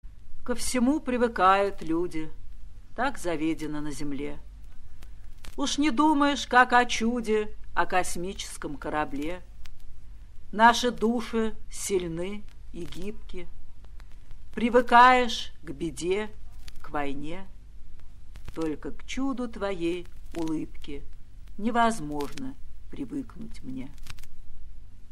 1. «Юлия Друнина – Ко всему привыкают люди (читает автор)» /
Drunina-Ko-vsemu-privykayut-lyudi-chitaet-avtor-stih-club-ru.mp3